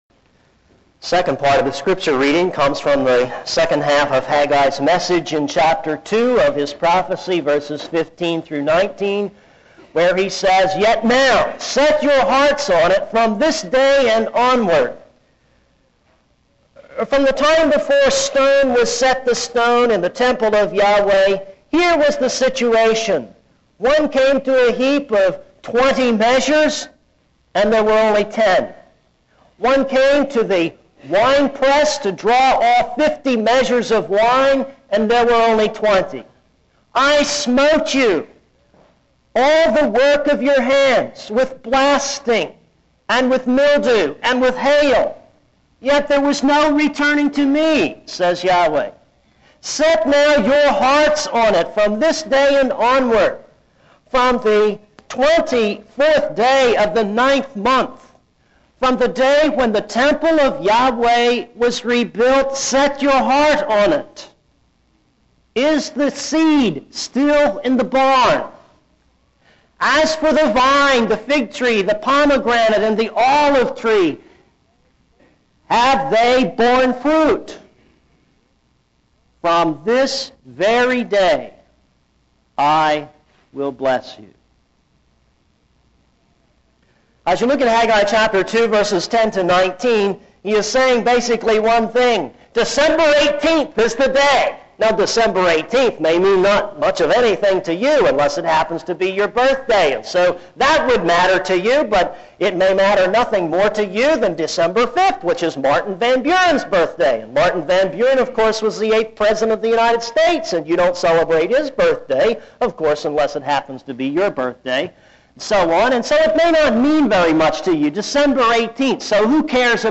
This is a sermon on Haggai 2:10-19.